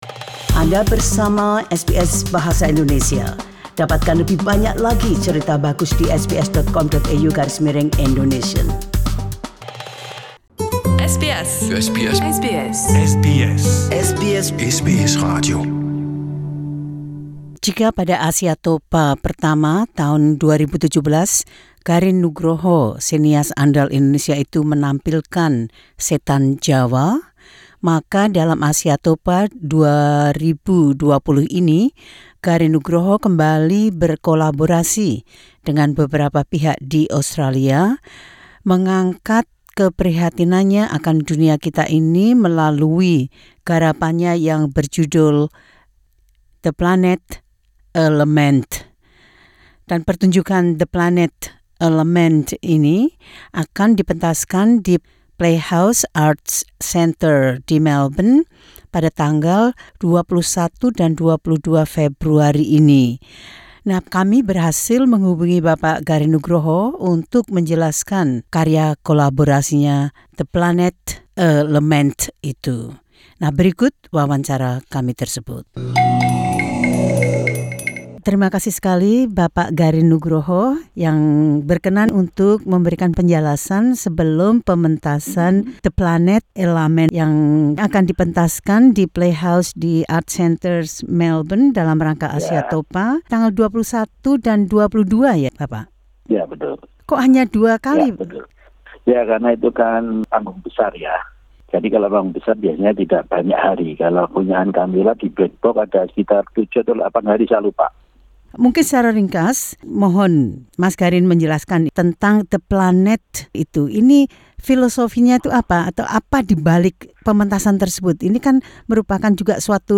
Garin Nugroho talks about bringing together the music, songs, performers and dance that make up his project called “The Planet-A Lament.”